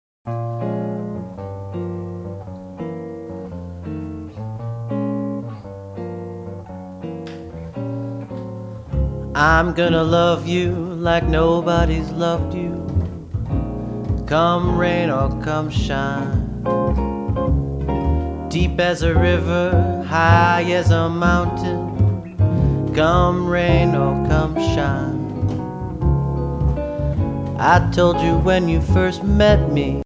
anything from Classic Jazz to Bebop to Modern Jazz